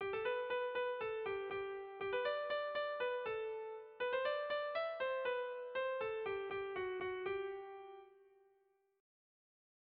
Dantzakoa
Lauko txikia (hg) / Bi puntuko txikia (ip)
A-B